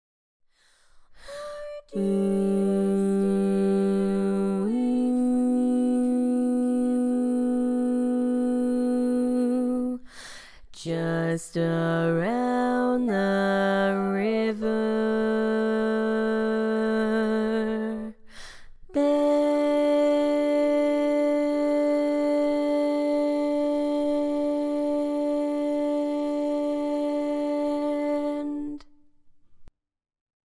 Key written in: D Major
Type: Female Barbershop (incl. SAI, HI, etc)